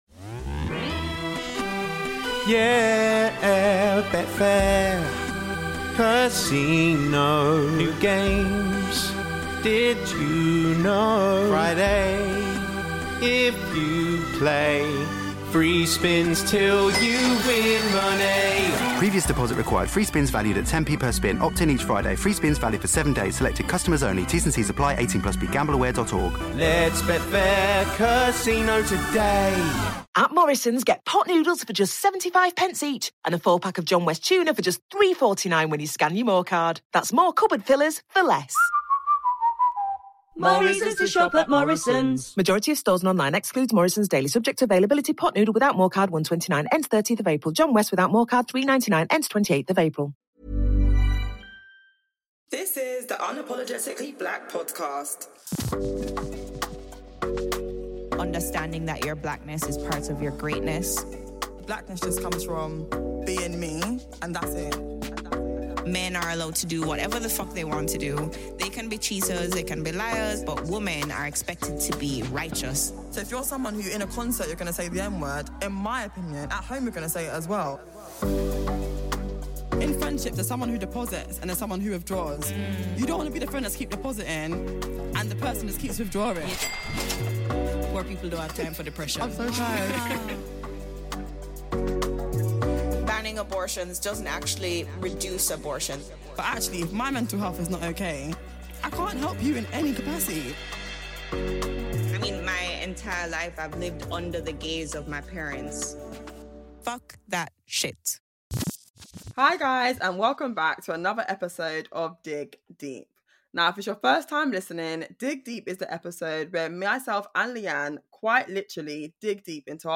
Dig deep is the episode all about taking YOU on our journey to building and sustaining a healthy sisterhood. We ask each other a series of tough and often intimate questions for the first time.